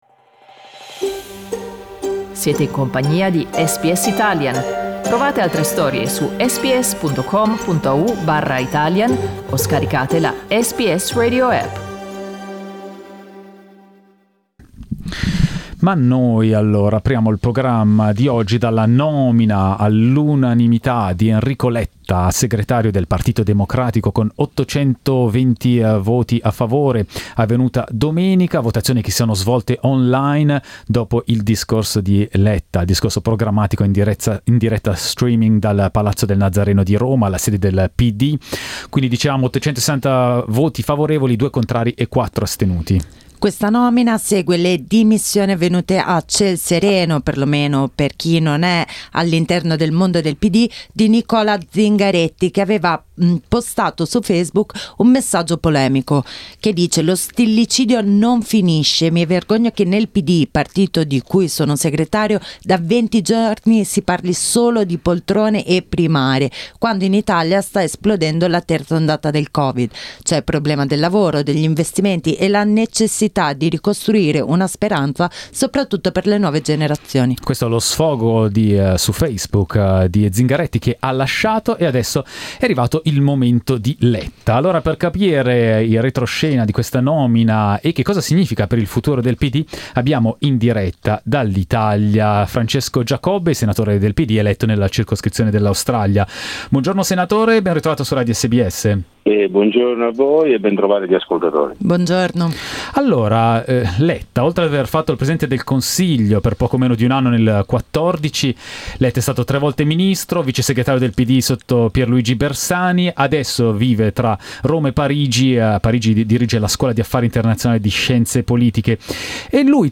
Votato all'unanimità, il nuovo segretario del Partito Democratico si presenta con 21 punti chiave per costruire un partito più unito e un'Italia più giovane. Il commento del senatore Francesco Giacobbe.